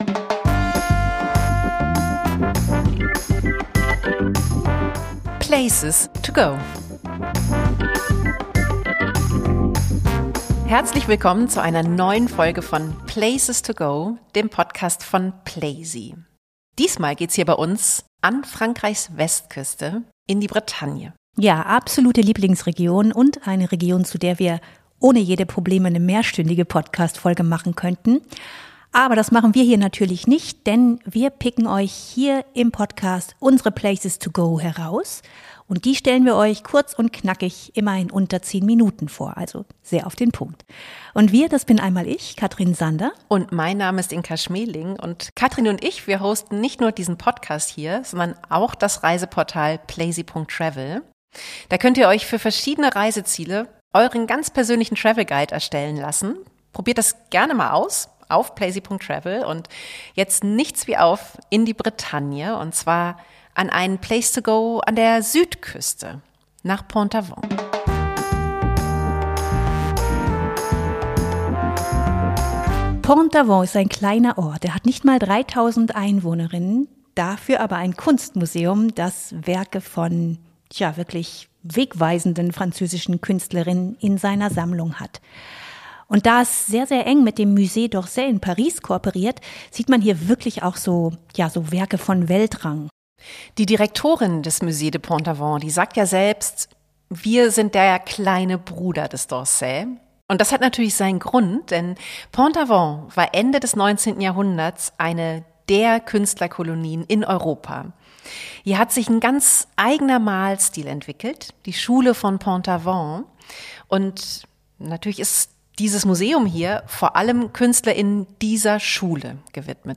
Wassily Kandinsky beeinflussten: Das erzählen dir die beiden Hosts